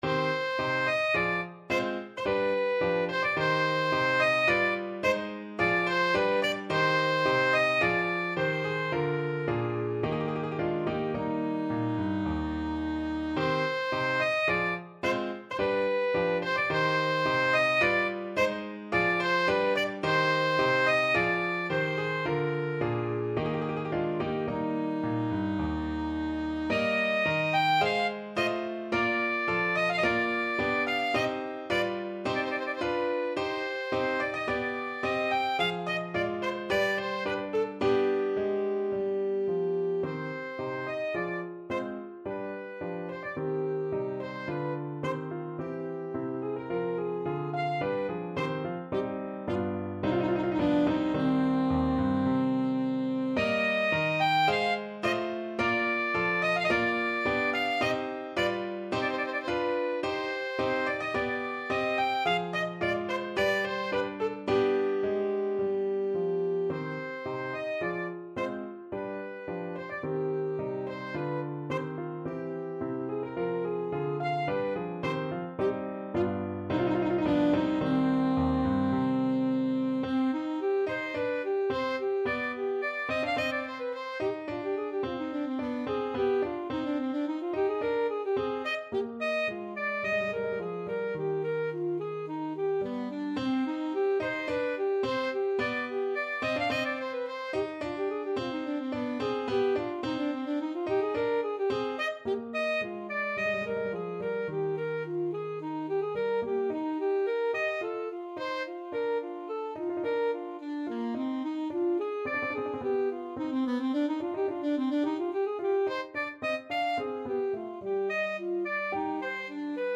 Alto Saxophone
3/4 (View more 3/4 Music)
~ = 54 Moderato
C minor (Sounding Pitch) A minor (Alto Saxophone in Eb) (View more C minor Music for Saxophone )
Classical (View more Classical Saxophone Music)